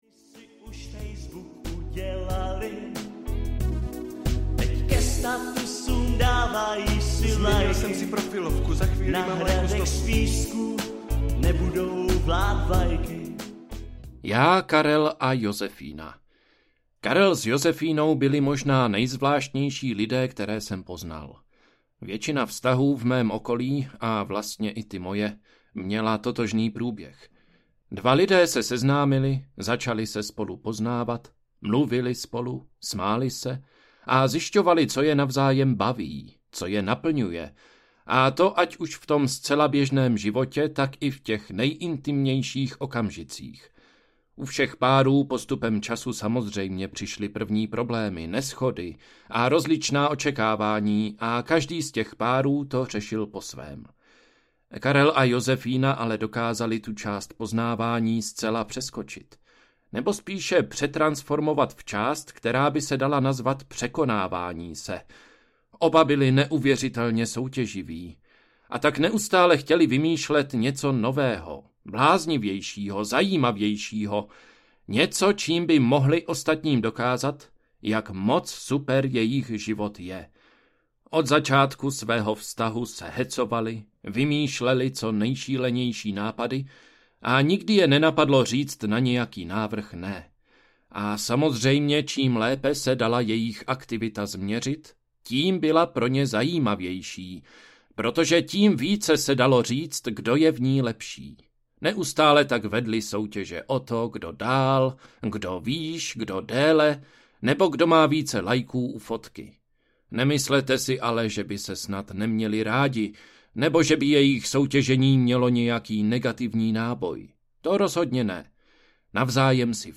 Láska, sex a lajky audiokniha
Ukázka z knihy